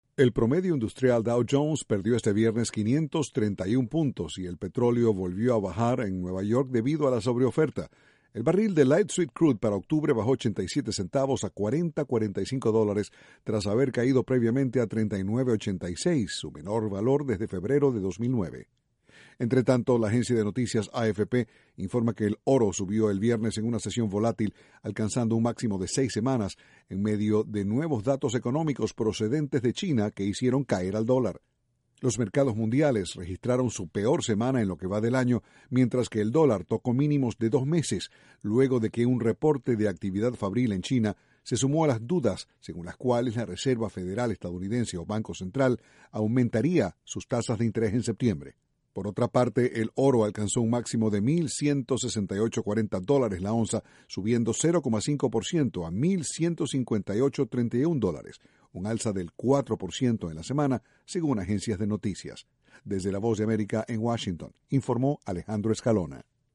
Noticias sobre economía de Estados Unidos